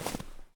update snow step sounds
snow_2.ogg